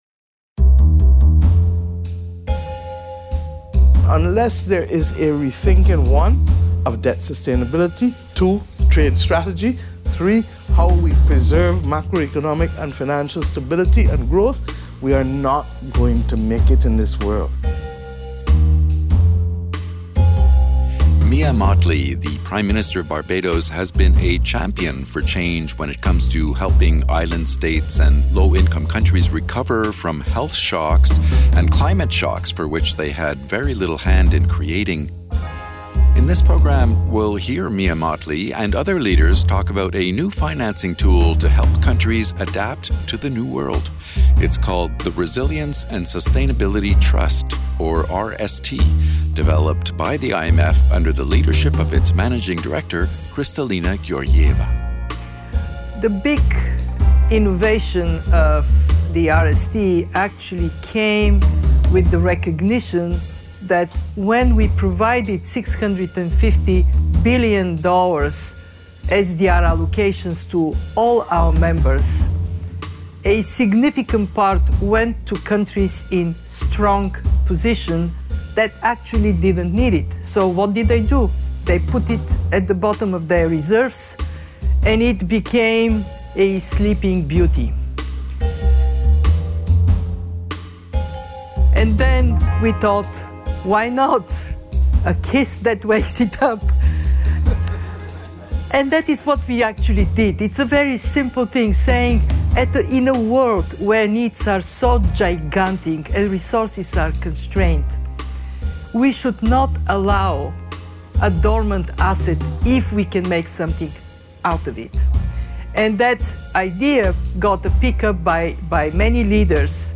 In this podcast, IMF Managing Director Kristalina Georgieva discusses the RST with Prime Minister Mia Motley of Barbados, Rwanda’s President Paul Kagame, WTO Director-General Ngozi Okonjo-Iweala, and Makhtar Diop, Managing Director of the International Finance Corporation. The discussion is moderated by Rajiv Shah, President of the Rockefeller Foundation.